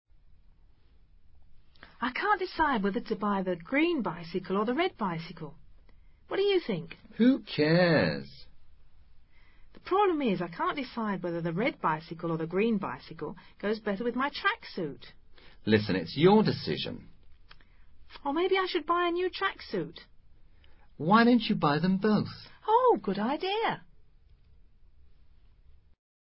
Archivo de audio que contiene un breve diálogo entre dos amigos que han ido de compras.